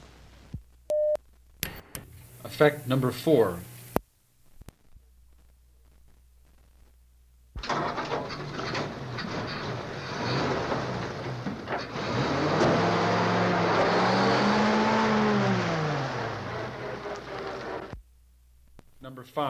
老式卡车 " G1404发动机咔哒声
描述：咔嗒作响的发动机开始挣扎，加速和溅出。 这些是20世纪30年代和20世纪30年代原始硝酸盐光学好莱坞声音效果的高质量副本。 40年代，在20世纪70年代早期转移到全轨磁带。我已将它们数字化以便保存，但它们尚未恢复并且有一些噪音。
Tag: 卡车 交通运输 光学 经典